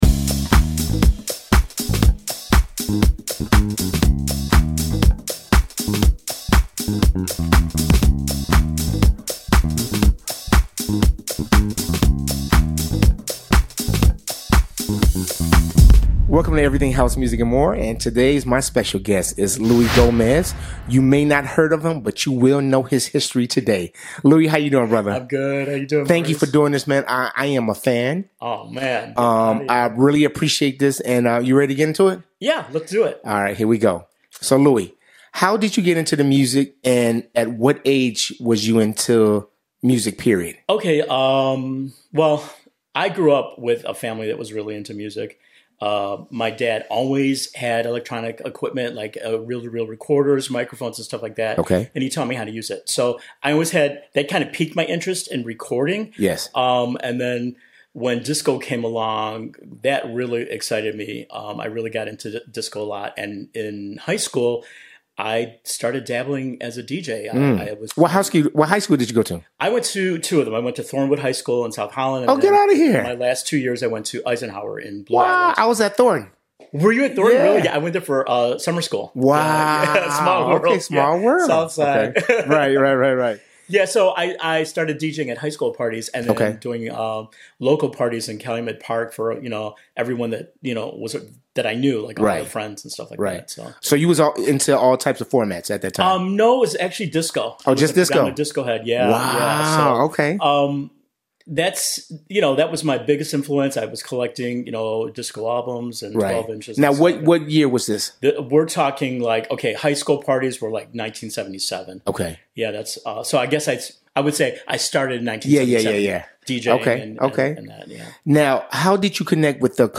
Music Interviews